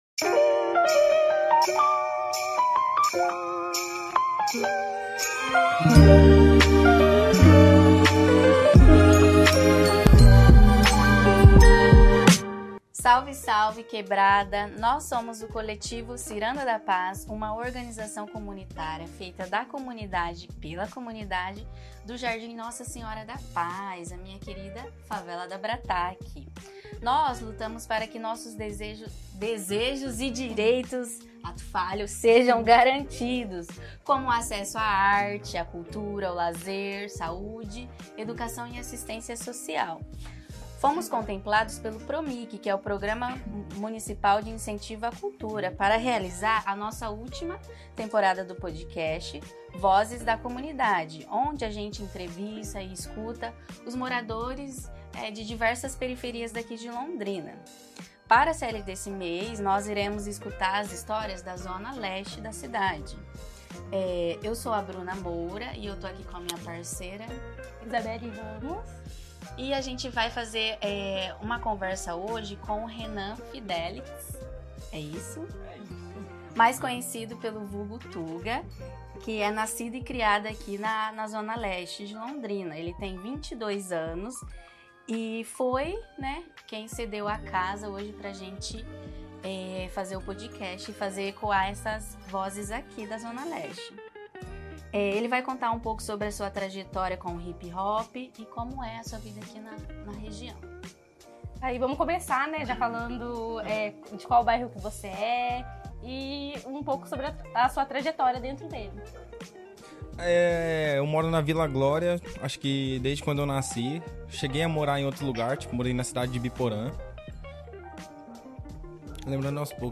Fomentado pelo PROMIC (Programa Municipal de Incentivo à Cultura) ele tem a proposta de ecoar as histórias potentes e resistentes de bairros periféricos da cidade de Londrina, a partir das vozes de seus próprios moradores.